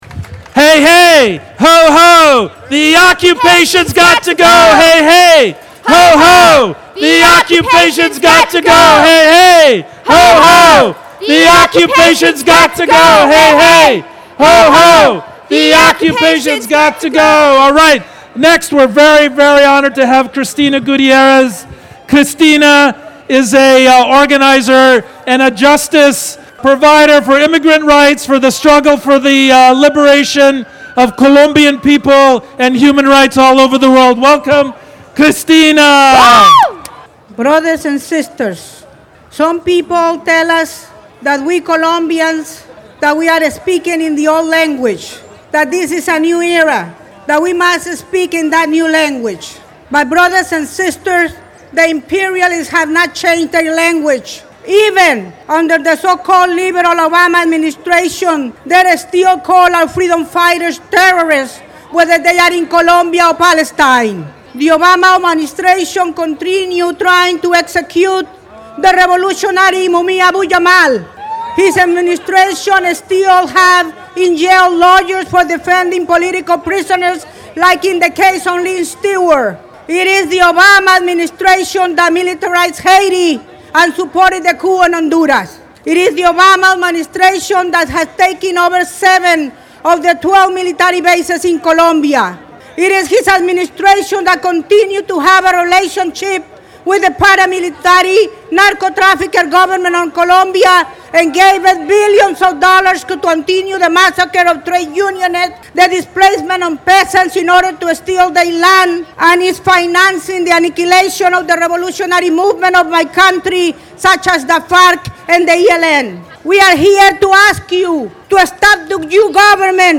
March 20th in San Francisco Civic Center was packed, and here are some key speakers that spoke this morning before the march went to the streets to demand the War stop now!!